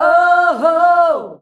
OOOHOO  F.wav